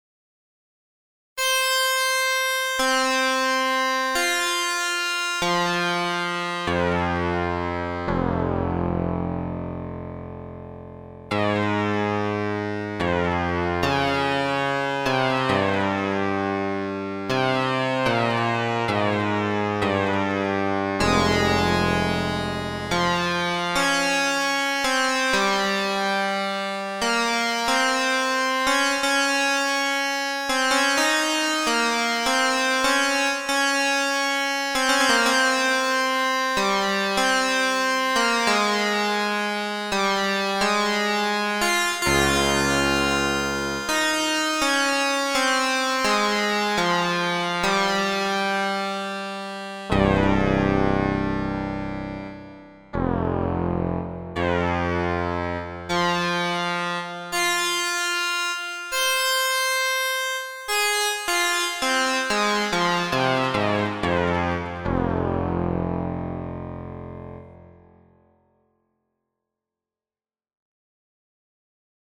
Delay och reverb pålagt i Cubase för att få stereobild och lite rymd på ljuden.
M6R-NarrowPulseSync.mp3